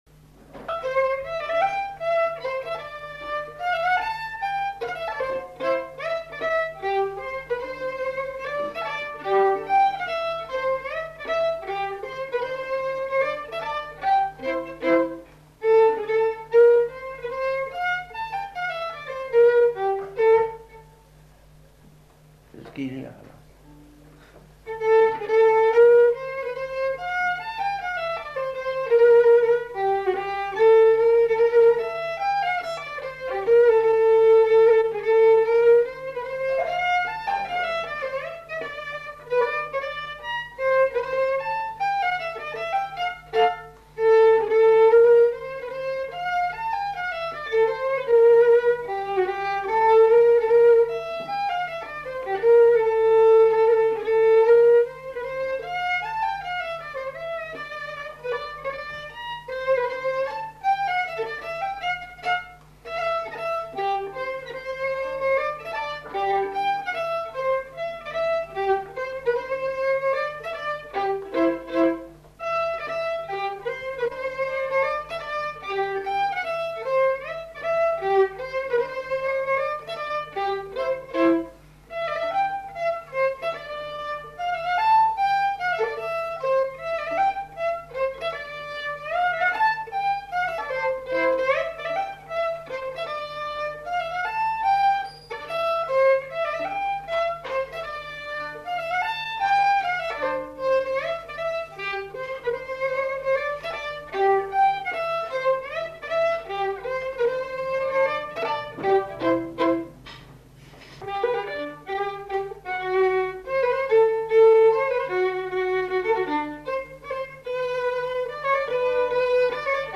Mazurka
Aire culturelle : Gabardan
Genre : morceau instrumental
Instrument de musique : violon
Danse : mazurka